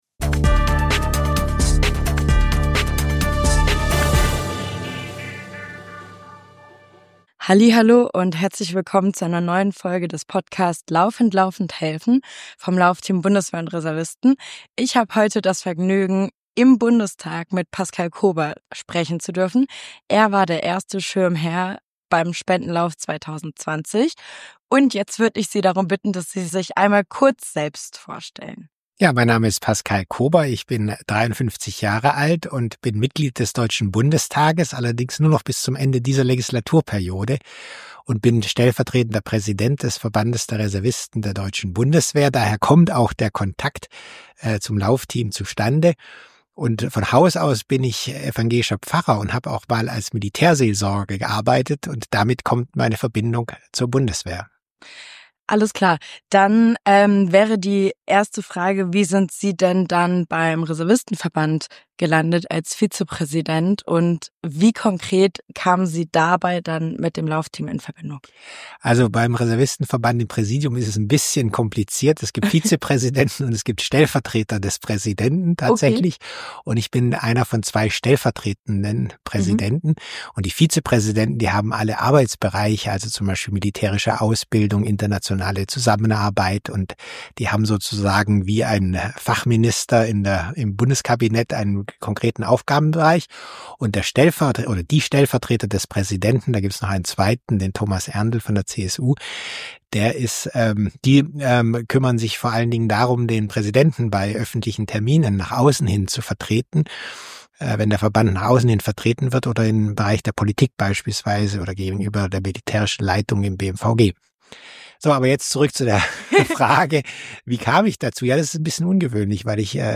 Pascal Kober zu Gast im Gespräch